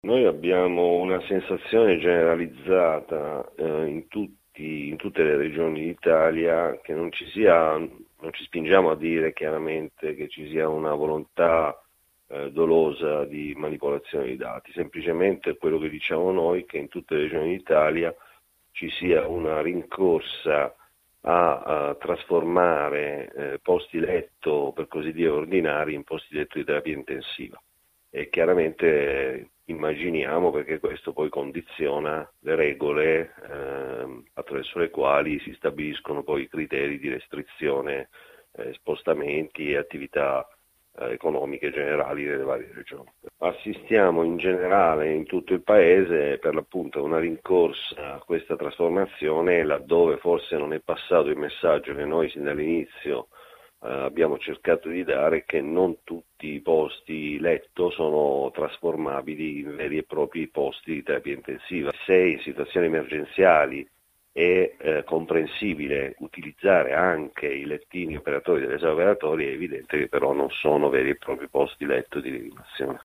Sentiamo la sua denuncia ai nostri microfoni.